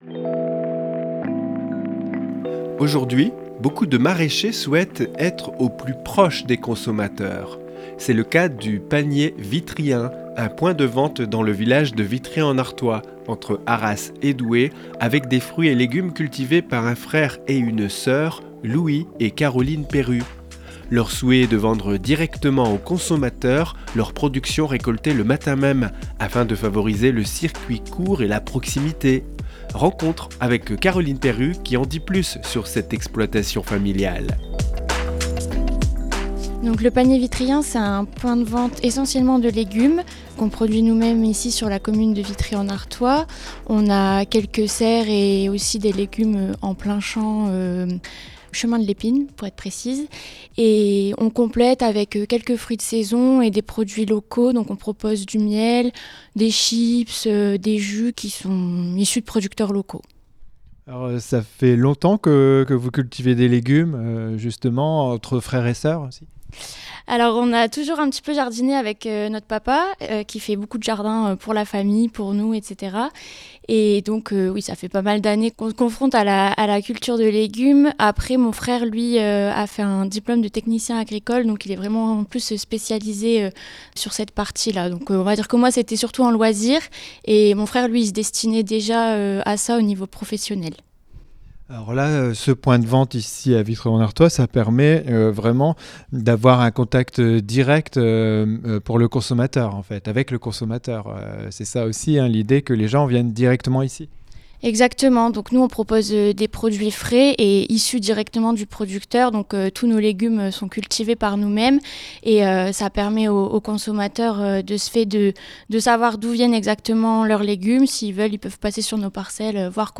REPORTAGE-2504-Le-Panier-Vitryen-a-Vitry-en-Artois.mp3